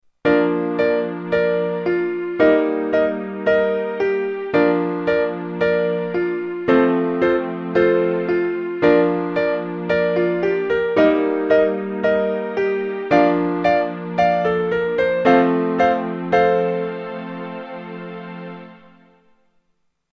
Genres: Sound Logo